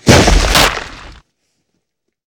thump.ogg